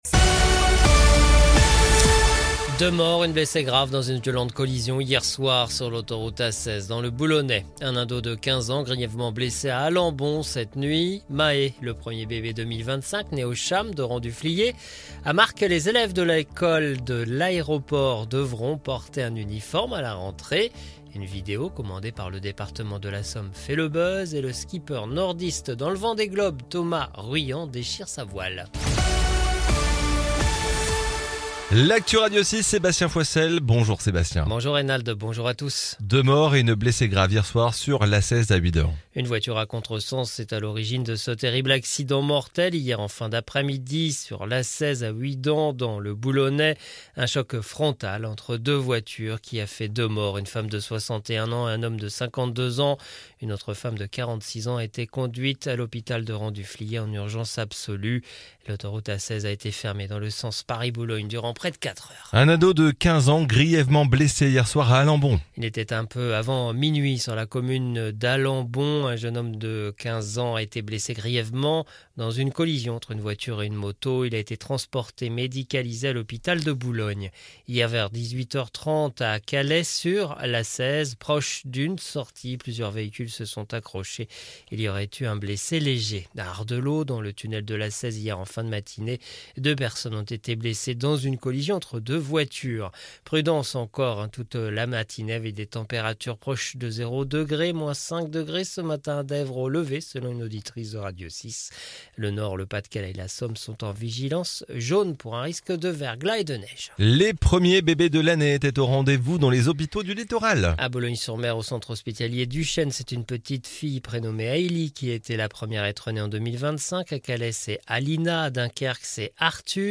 Le journal du vendredi 3 janvier 2025